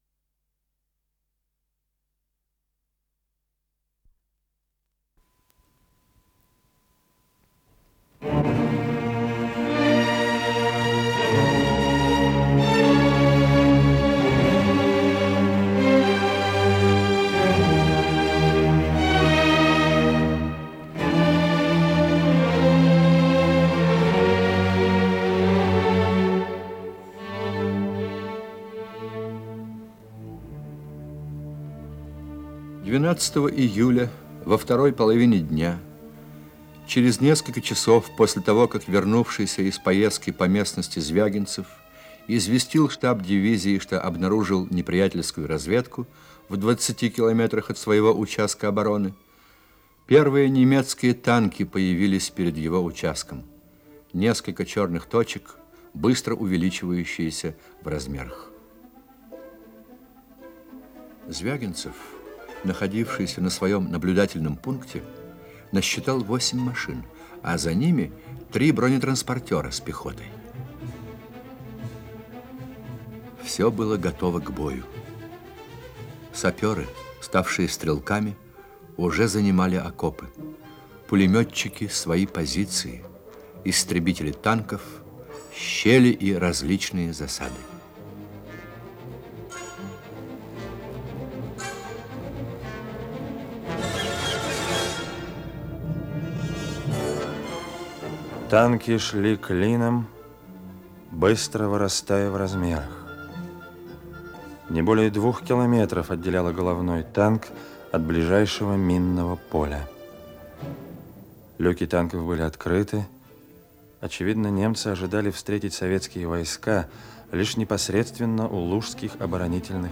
Исполнитель: Артисты московских театров
Инсценированные страницы романа, передача 5-я